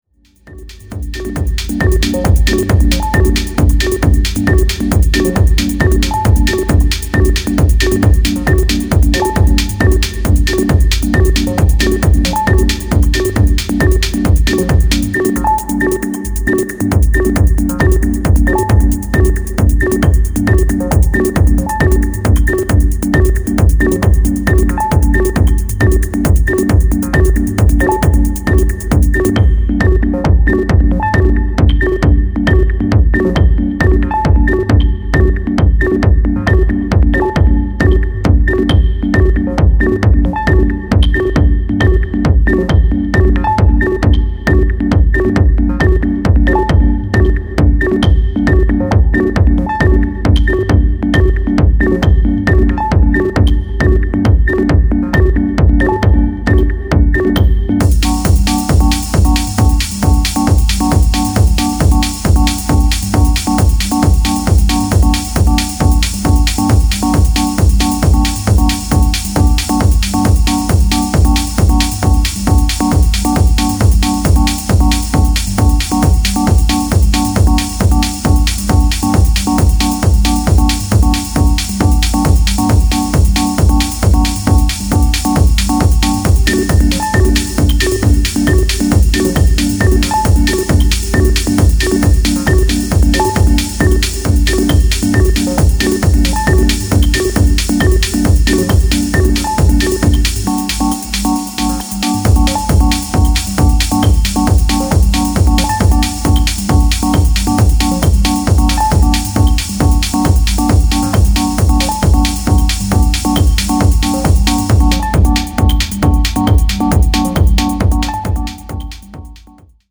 FUNCTIONAL TECHNO CRAFTED TO ELEVATE